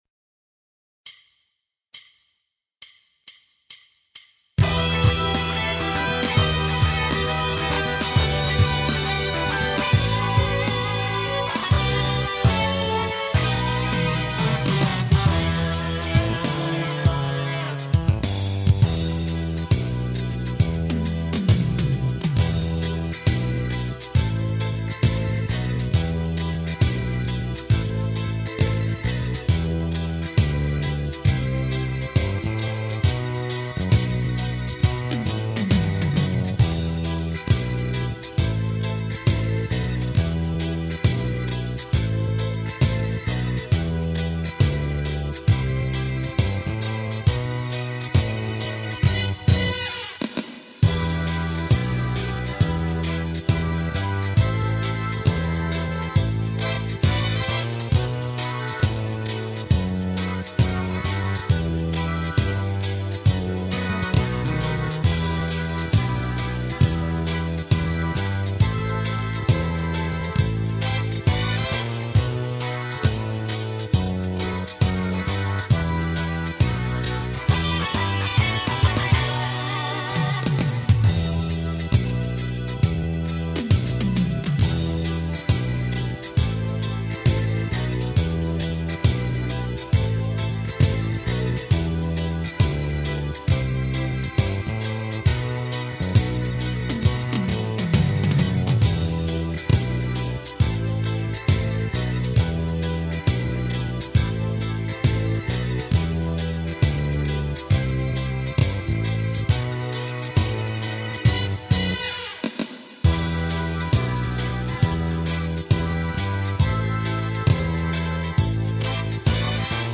И Вам - минусовка 3 куплета счастья!